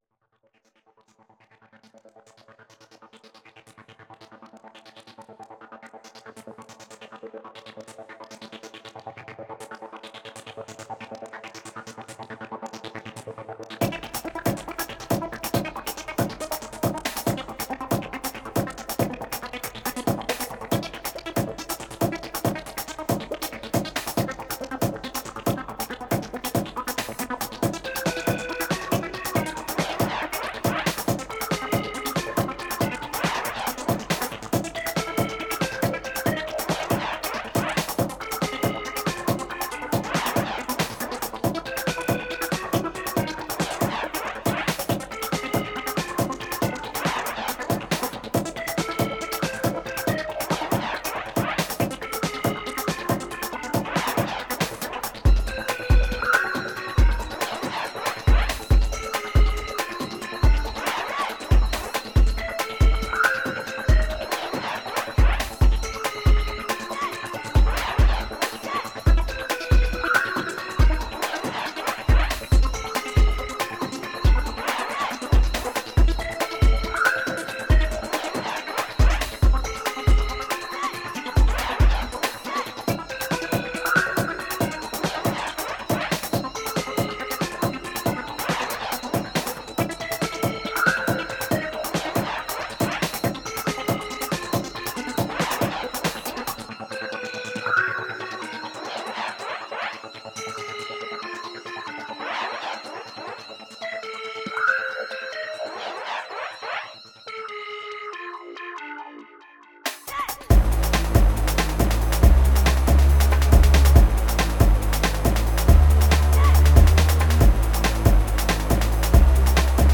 Great work, very moody.
The only real drawback is the bassdrumloop. To hard and to little bass imo.
This was actually the first VST plugin track I wrote on madtracker.
I craft together different loops for a good rhythmn and plenty of depth.